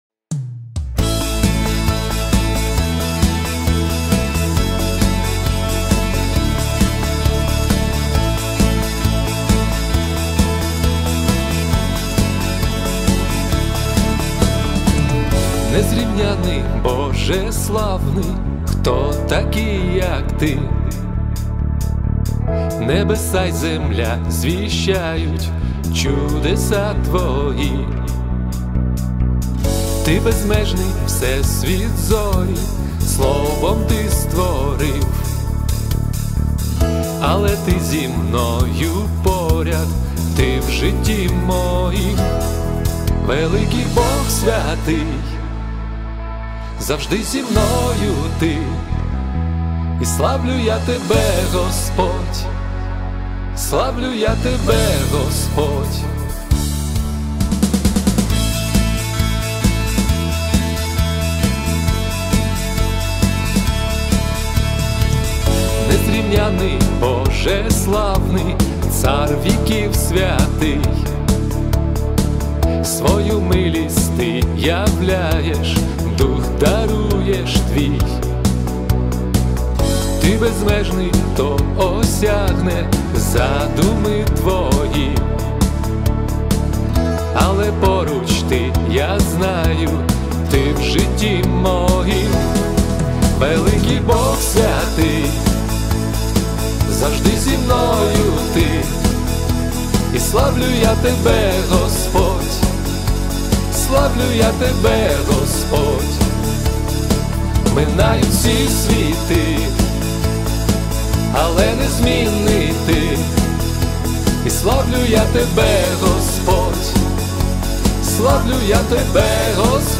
397 просмотров 77 прослушиваний 16 скачиваний BPM: 134